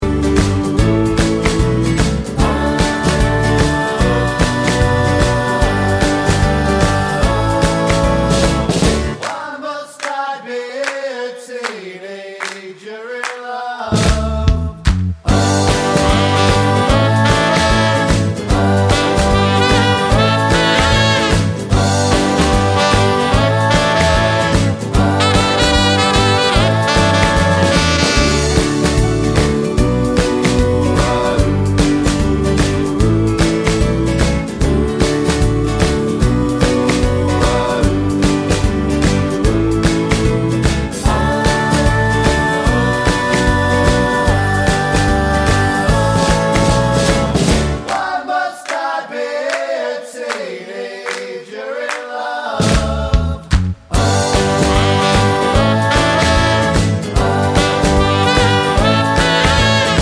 no Backing Vocals Rock 'n' Roll 3:01 Buy £1.50